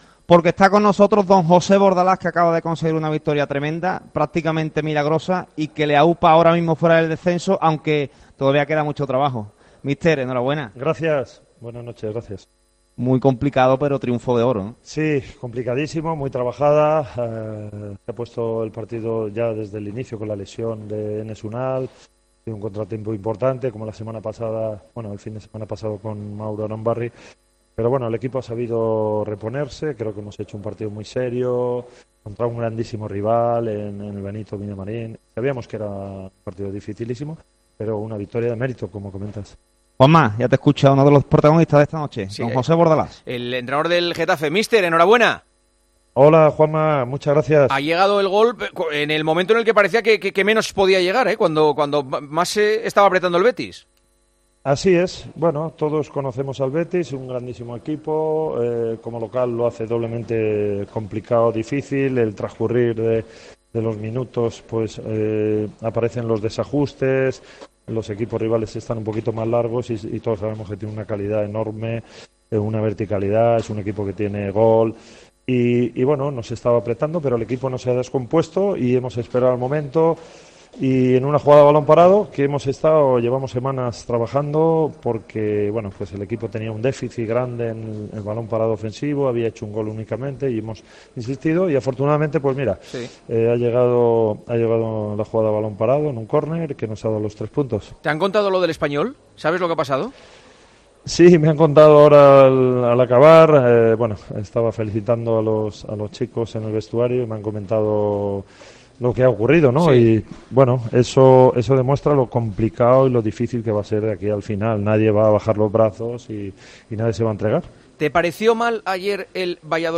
El entrenador del Getafe habló con Juanma Castaño en El Partidazo de COPE tras la victoria de su equipo en el Villamarín: "Esto va a ser complicado y difícil hasta el final".